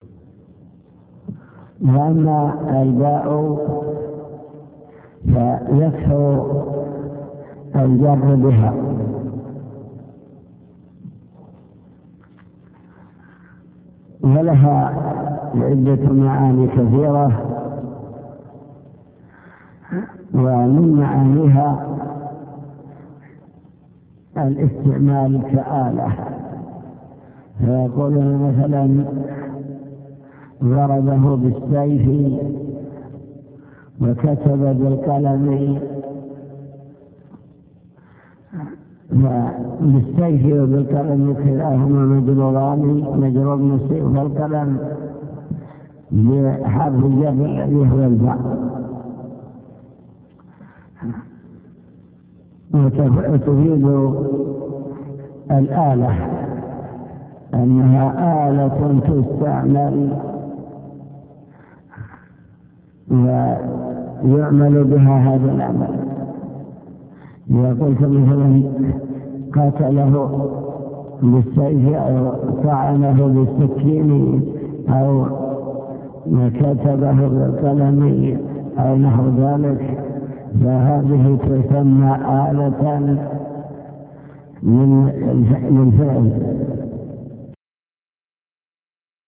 المكتبة الصوتية   تسجيلات - كتب  شرح كتاب الآجرومية  موقع سماحة الشيخ بن جبرين-رحمه الله..شرح كتاب الآجرومية